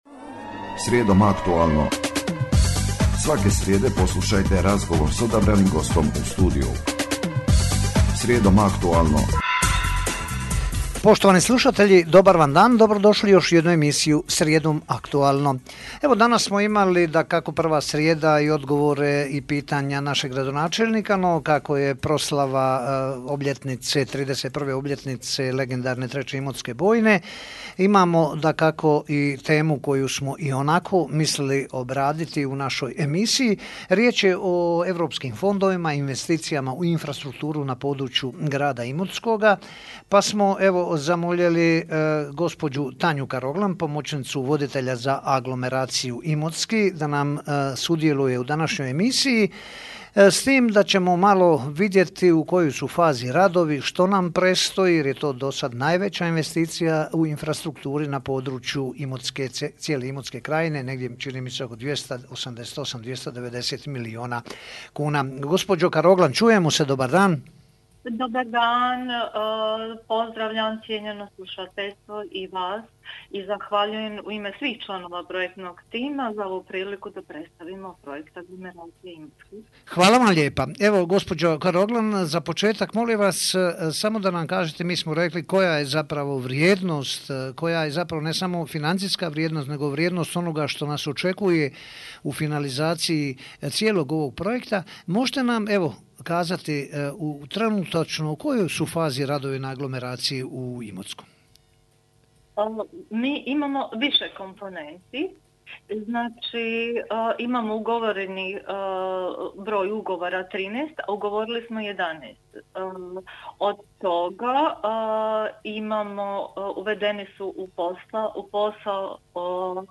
Poslušajte radijsku emisiju"Srijedom aktualno"